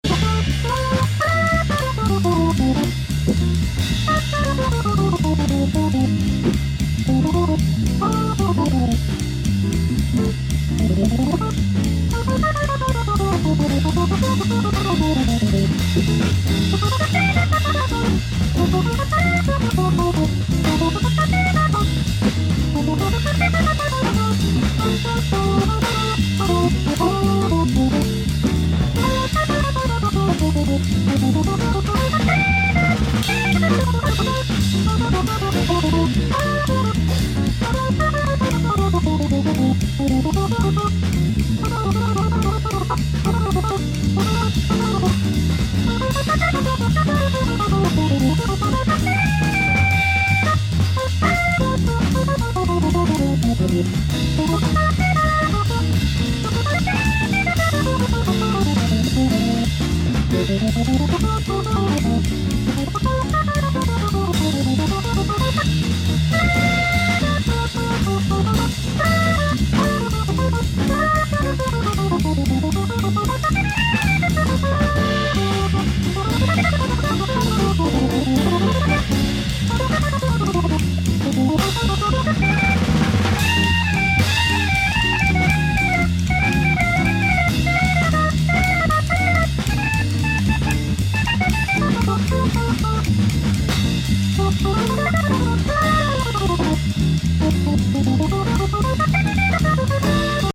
trios d'orgue